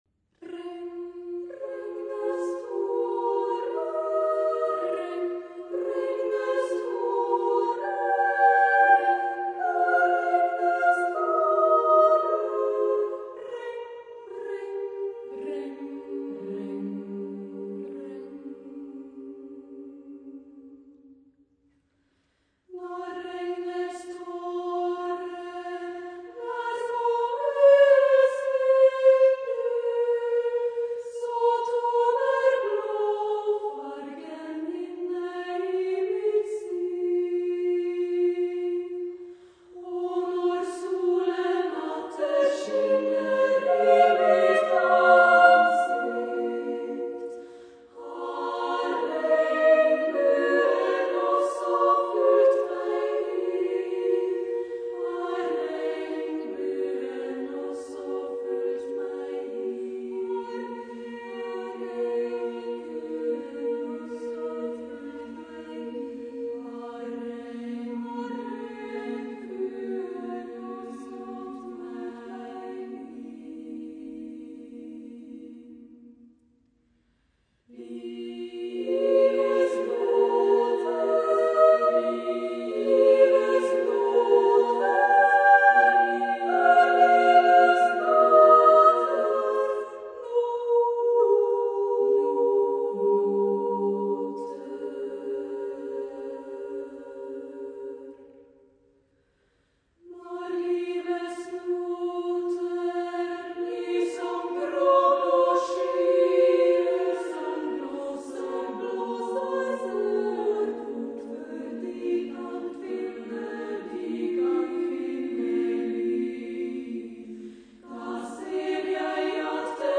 for female choir (SSAA) a cappella